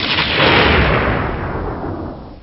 thunder2.wav